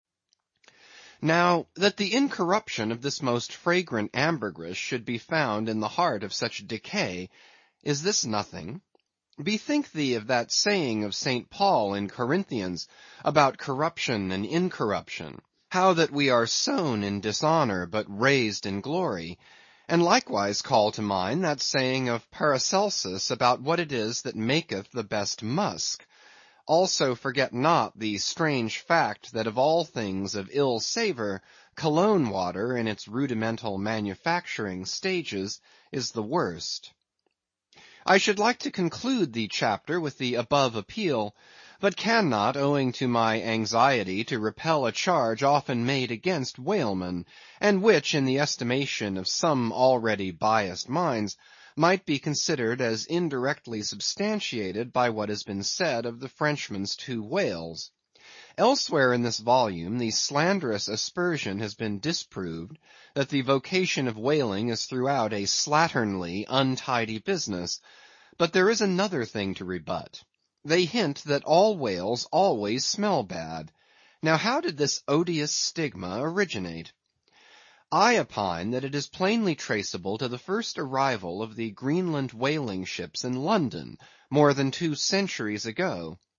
英语听书《白鲸记》第797期 听力文件下载—在线英语听力室